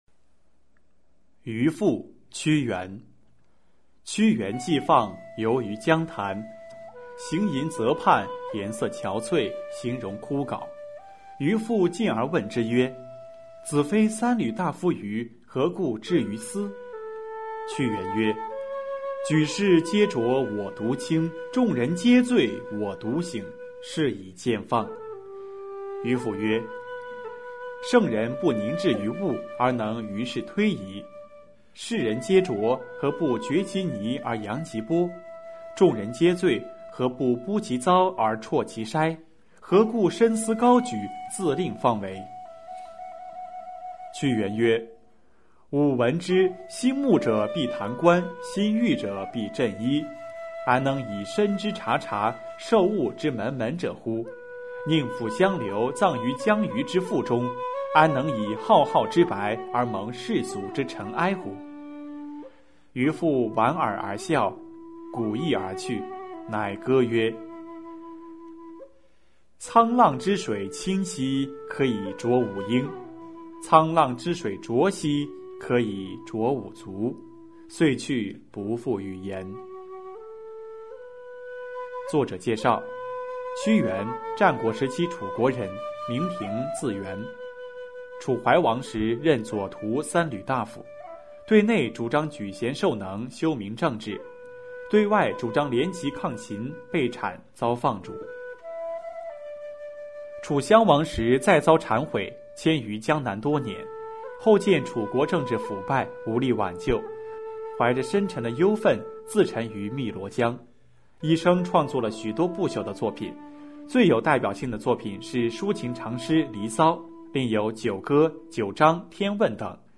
首页 视听 语文教材文言诗文翻译与朗诵 高中语文必修五